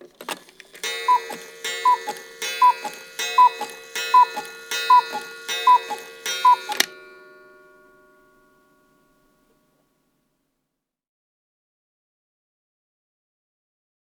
cuckoo_strike6.L.wav